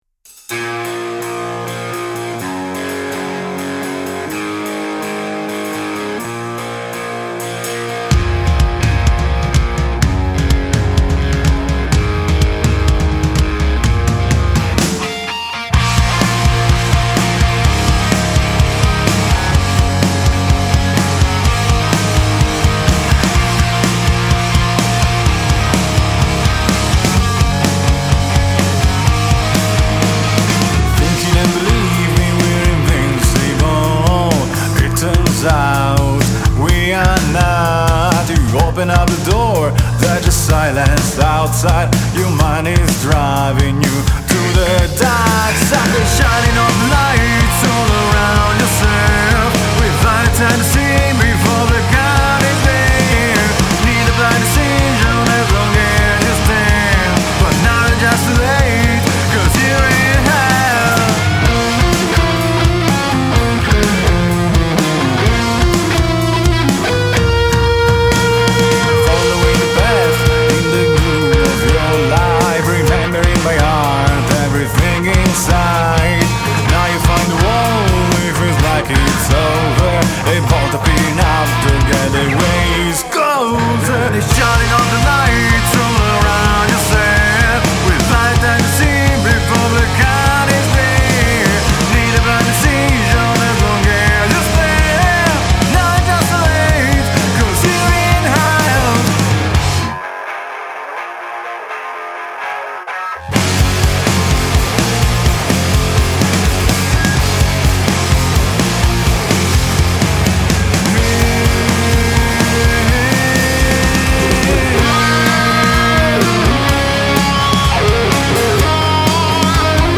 Alternative Rock Band from Genova.